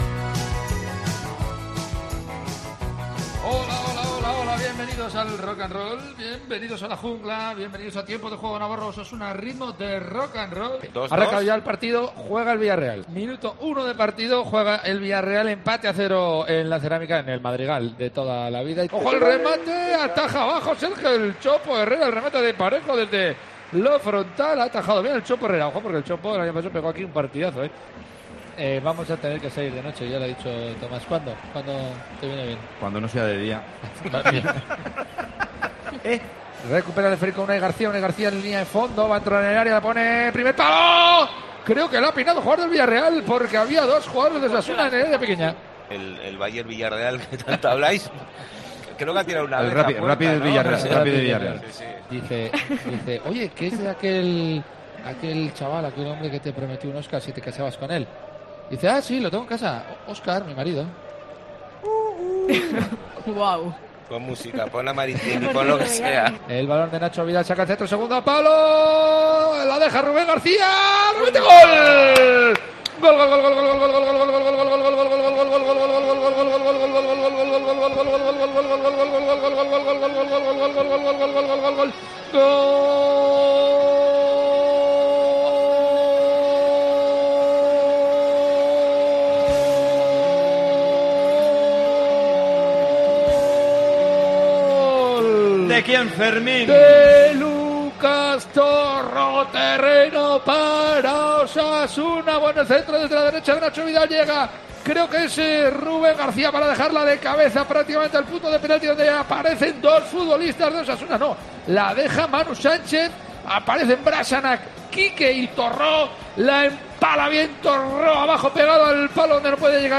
Resumen del Villarreal 1 - Osasuna 2 en Tiempo de Juego Navarro
Vive los partidos de Osasuna en Tiempo de Juego Navarro a ritmo de Rock & Roll